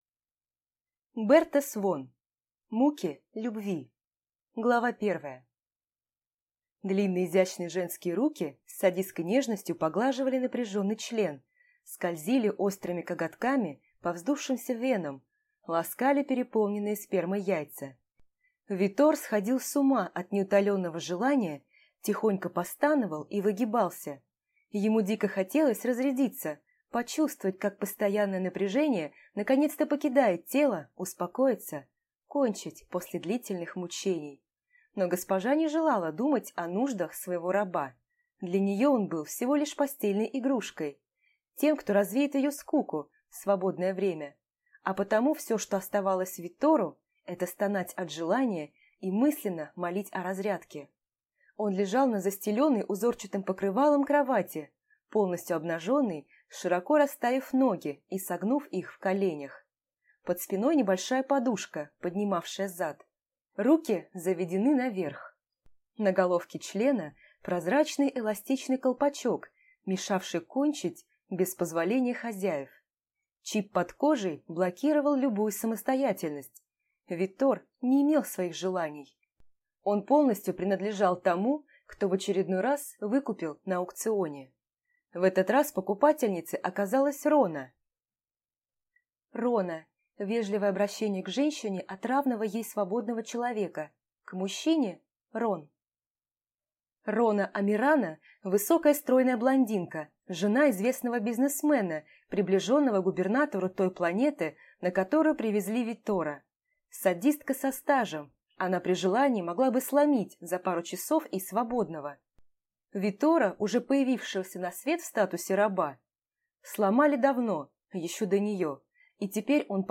Aудиокнига
Прослушать и бесплатно скачать фрагмент аудиокниги